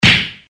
Descarga de Sonidos mp3 Gratis: efectos sonoros para videos 9.